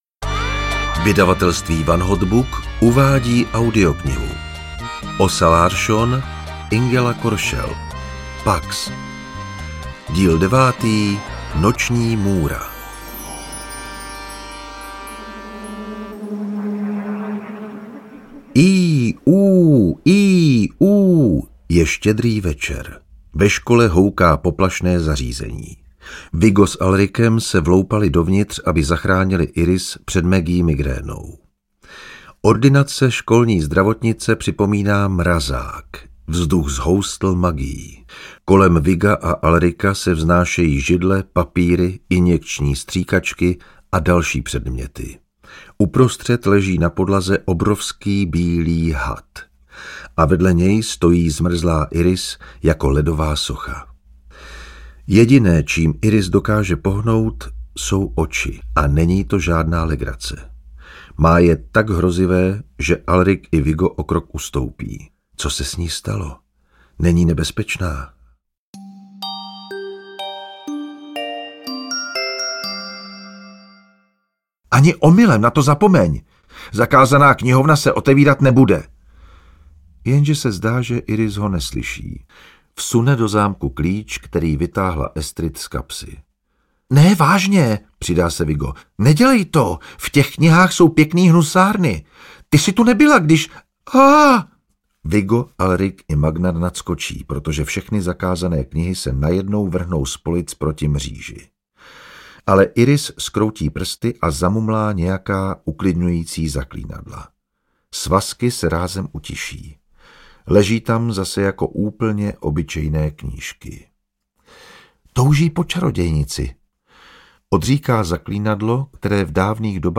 Pax 9: Noční můra audiokniha
Ukázka z knihy